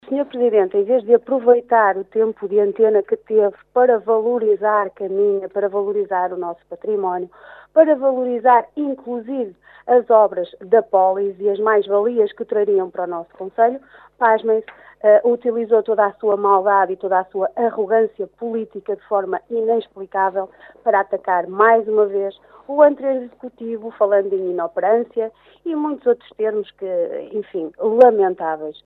Declarações de Liliana Silva à Rádio Caminha